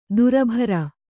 ♪ durabhara